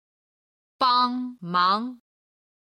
帮忙　(bāng máng)　手伝う、助ける